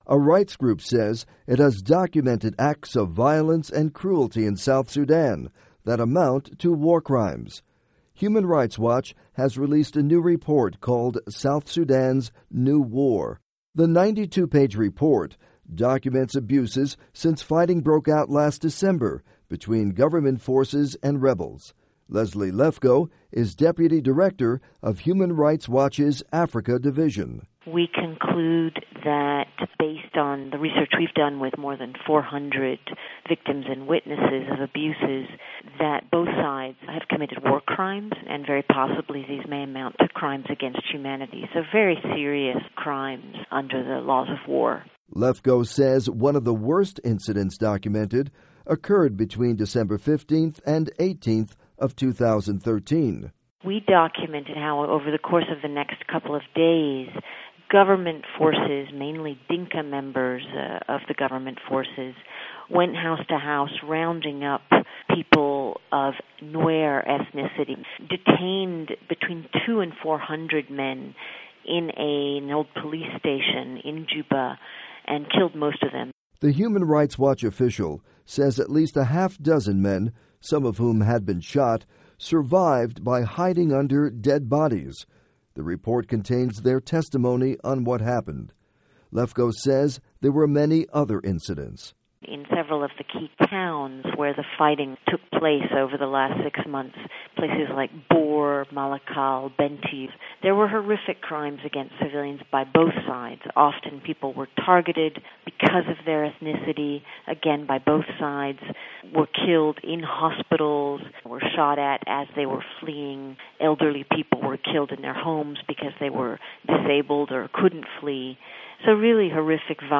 report on alleged war crimes in South Sudan
by Voice of America (VOA News)